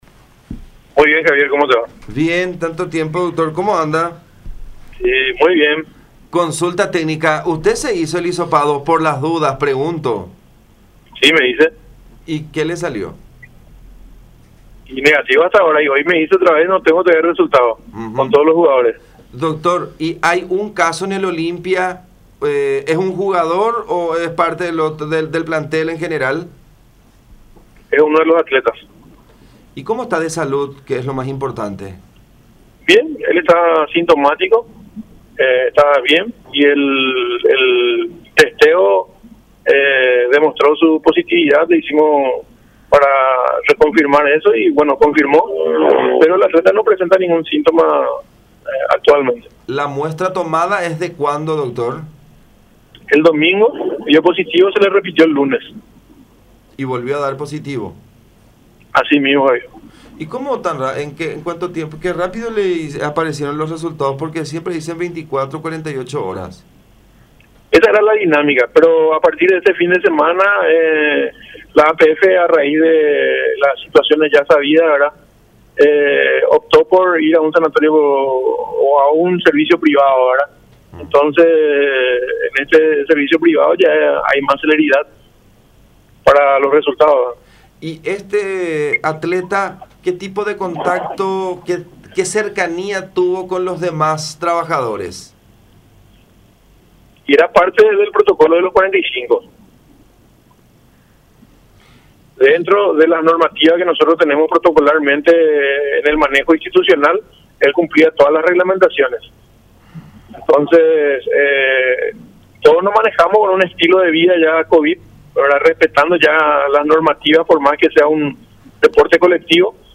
en diálogo con La Unión.